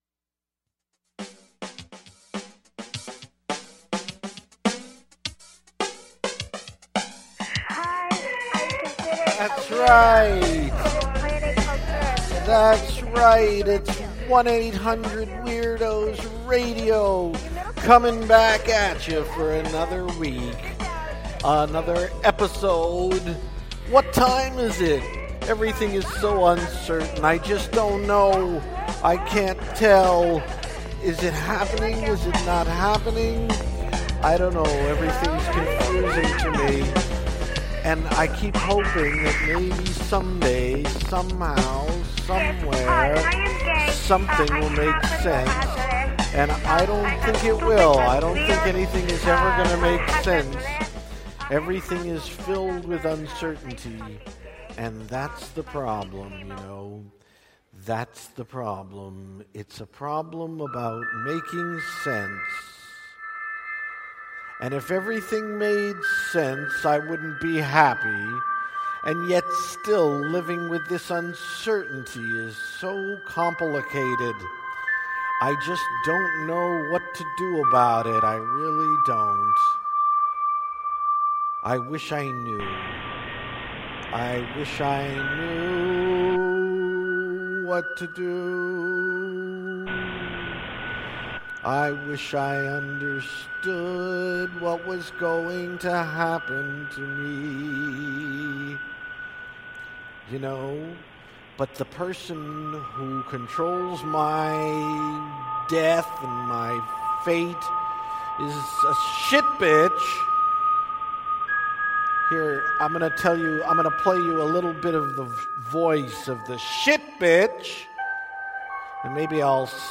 I sing the song "Shitbitch", and play a tiny keyboard version of Scott Joplin's "Solace".